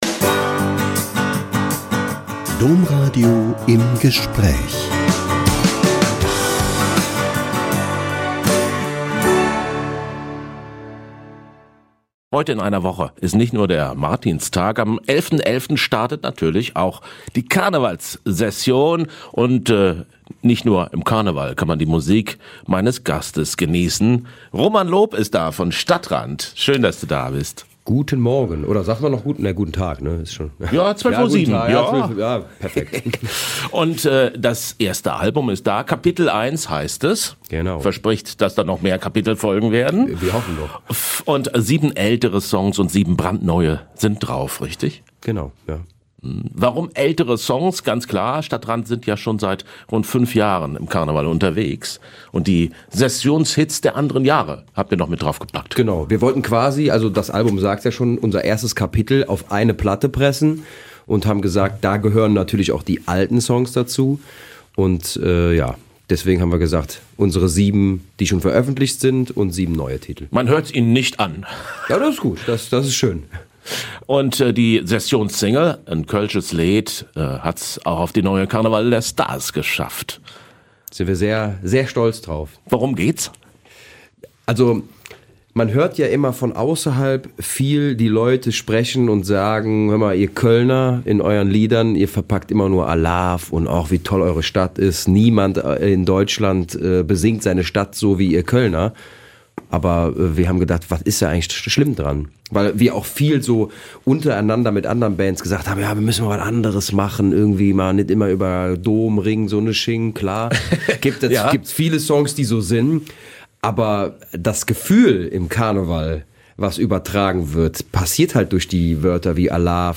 Neue Sessionssingle von StadtRand - Ein Interview mit Roman Lob (StadtRand, Teilnehmer beim Eurovision Song Contest 2012) # Menschen und Musik ~ Im Gespräch Podcast